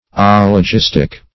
Search Result for " oligistic" : The Collaborative International Dictionary of English v.0.48: Oligist \Ol"i*gist\, Oligistic \Ol`i*gis"tic\, a. [Gr.